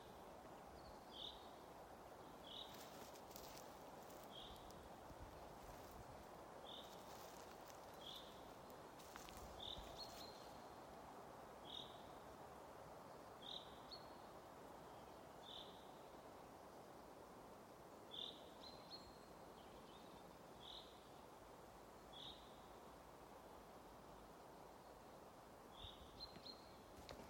Žubīte, Fringilla coelebs
StatussUzturas ligzdošanai piemērotā biotopā (B)